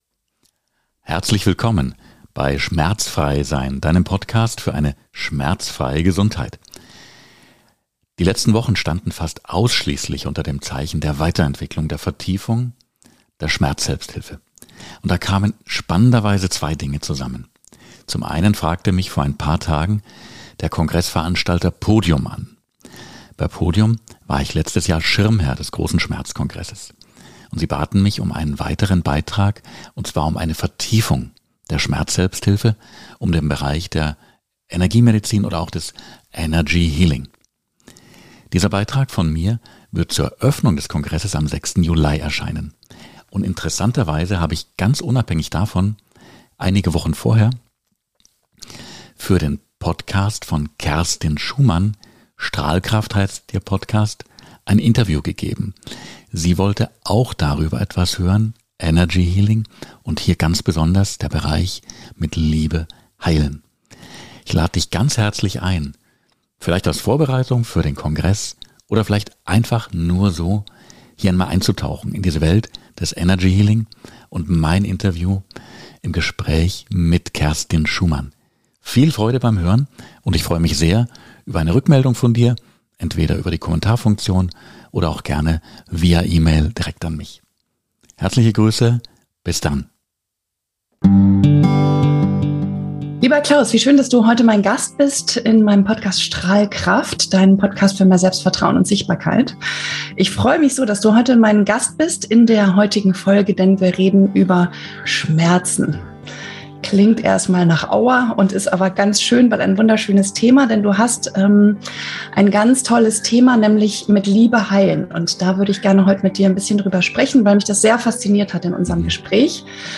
Einen ersten Einblick gebe ich in diesem Live-Interview. Körperlicher Schmerz entsteht auf verschiedene Weisen und kann sowohl anatomische als auch emotionale Ursachen haben.